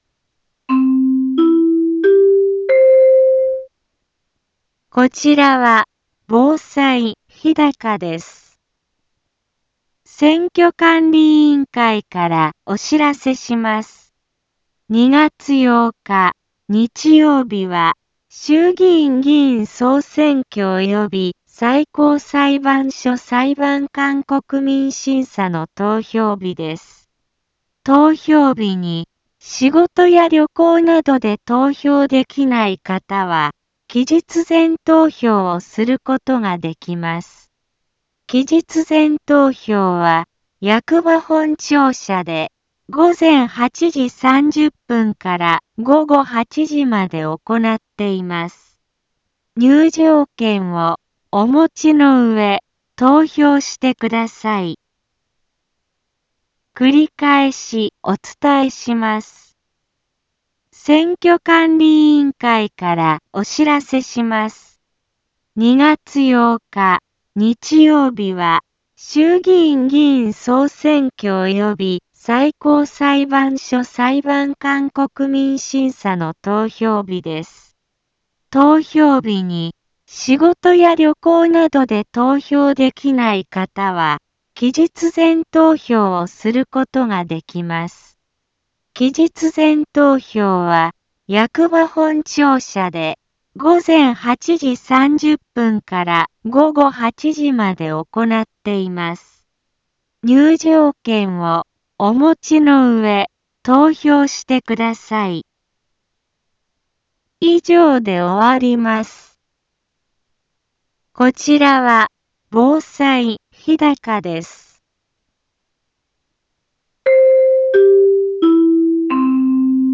一般放送情報
Back Home 一般放送情報 音声放送 再生 一般放送情報 登録日時：2026-01-28 15:04:12 タイトル：衆議院議員総選挙投票棄権防止の呼びかけ インフォメーション： こちらは、防災日高です。